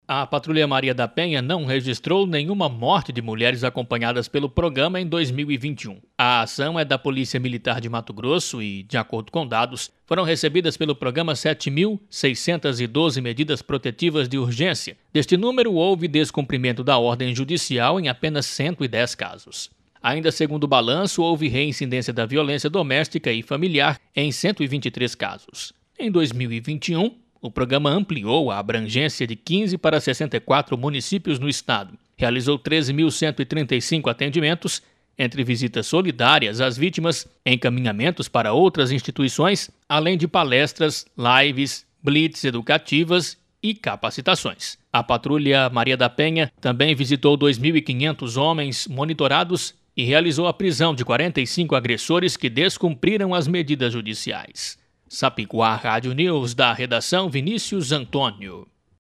Boletins de MT 08 mar, 2022